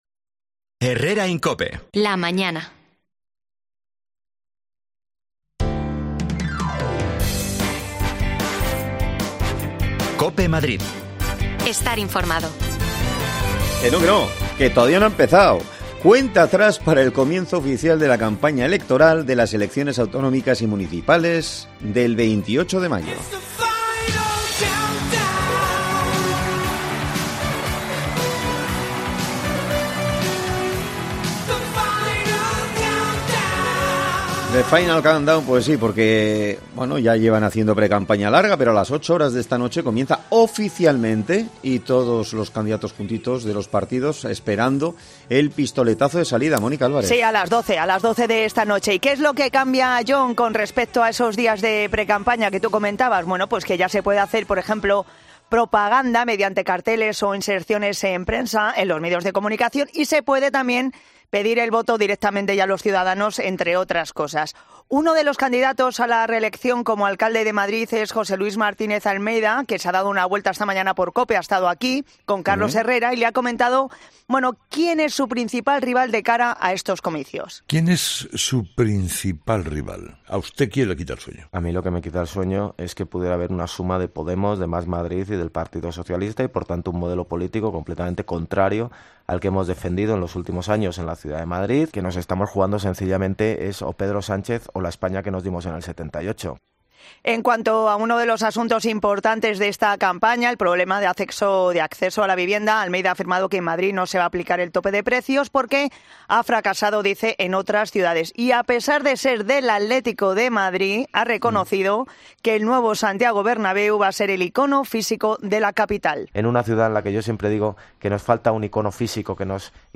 AUDIO: Esta medianoche comienza la campaña electoral. Uno de los candidatos a la alcaldia Martinez Almeida se ha pasado por los micrófonos de Cope...
Las desconexiones locales de Madrid son espacios de 10 minutos de duración que se emiten en COPE , de lunes a viernes.